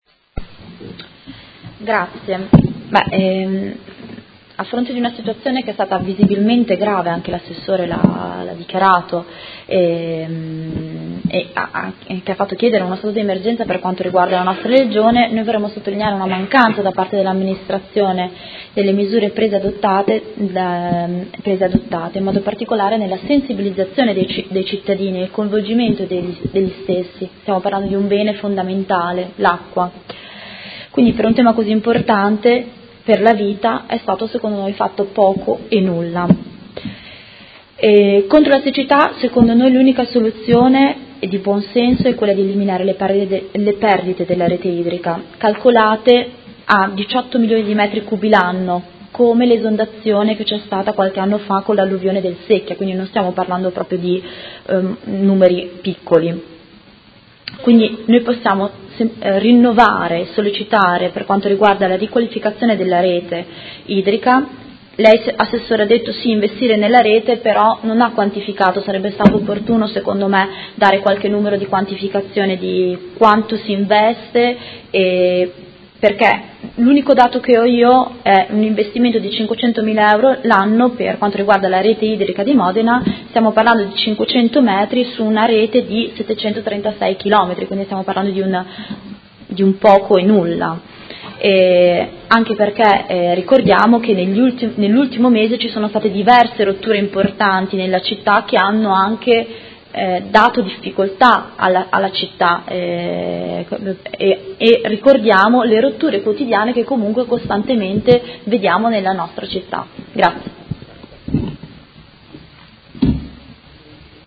Seduta del 19/10/2017 Replica a risposta Assessore Guerzoni. Interrogazione dei Consiglieri Bussetti, Scardozzi e Bortolotti (M5S) avente per oggetto: Situazione siccità – Primo firmatario Consigliere Bussetti